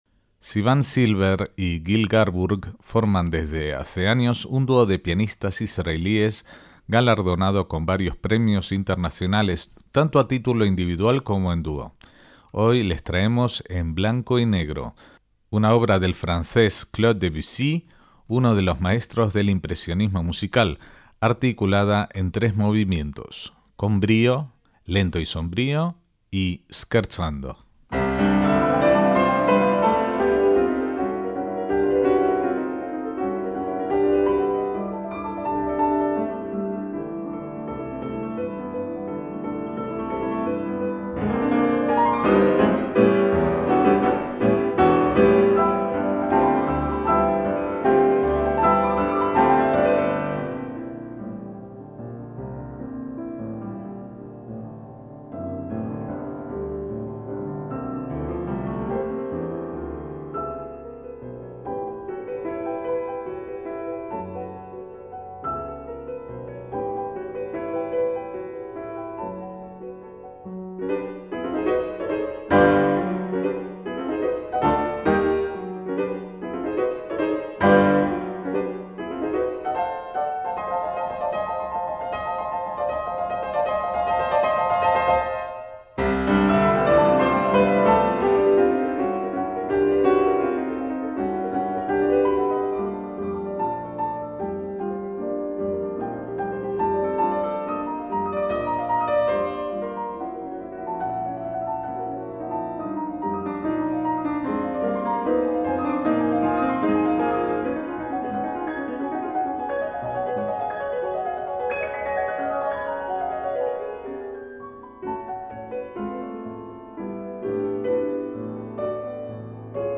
MÚSICA CLÁSICA
En esta ocasión interpretan "En blanc et noir" (En blanco y negro). una suite de tres piezas para dos pianos compuesta en 1915 por el impresionista francés Claude Debussy.